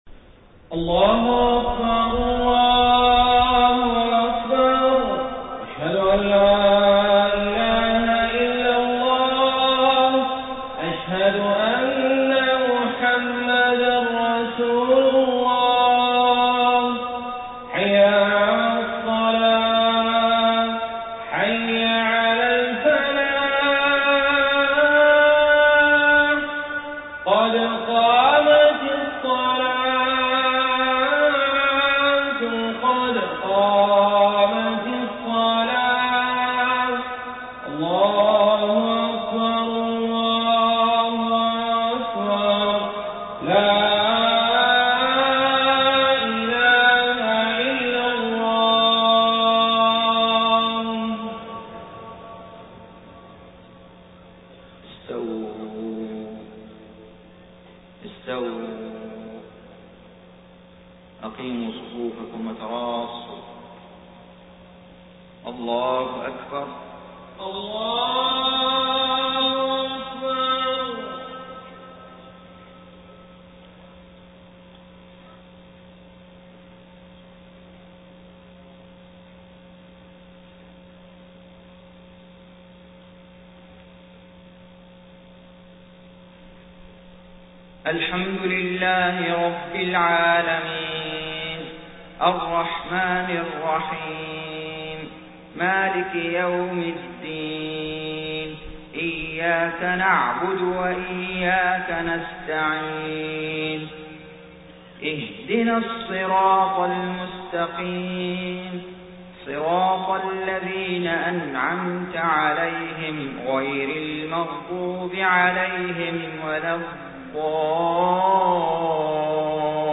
صلاة الفجر 8 صفر 1431هـ فواتح سورة الأنبياء 1-25 > 1431 🕋 > الفروض - تلاوات الحرمين